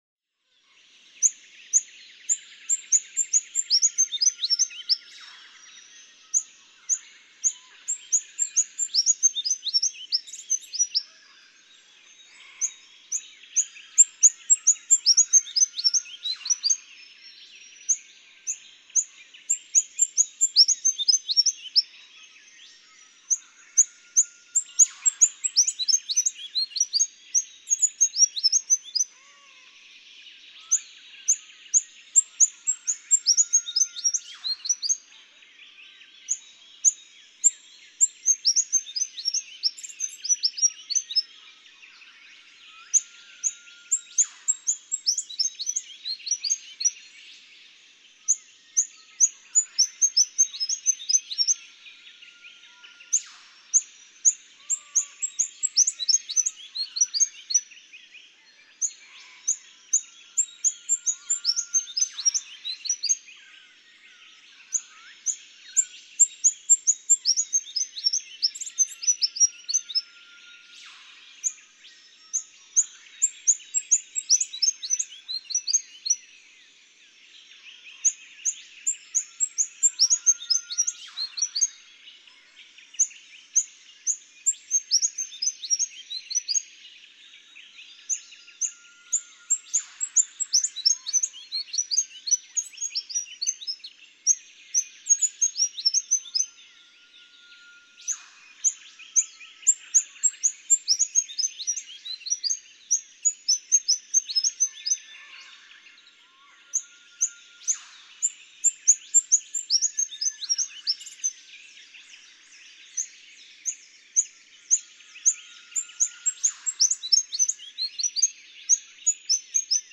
Rufous fantail
With whipbirds cracking in the background, this male rufous fantail sings his high-pitched song almost continuously during the dawn chorus.
Goomburra Section of the Main Range National Park, Queensland.